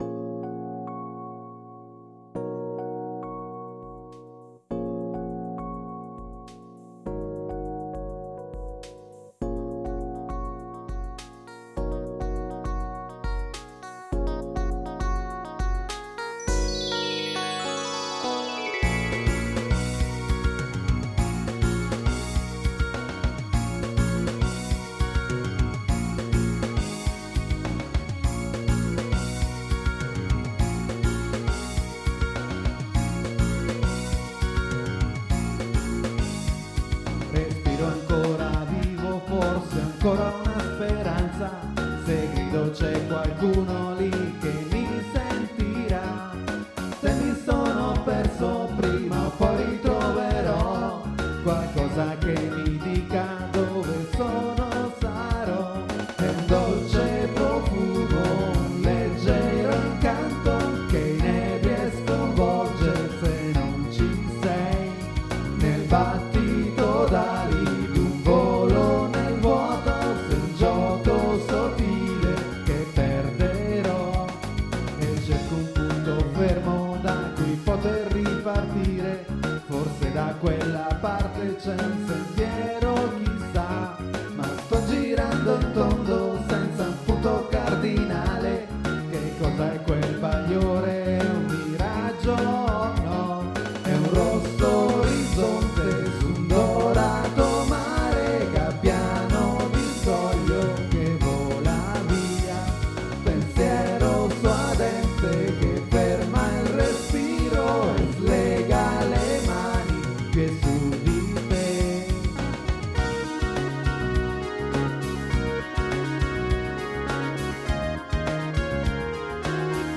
• Multitrack Recorder Zoom MRS-4
• Mic AKG D 40 S